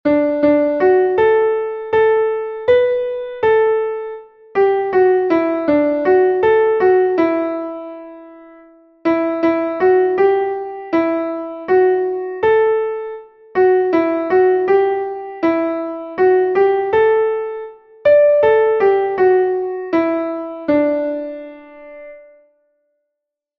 Text und Melodie: Volkslied aus Schlesien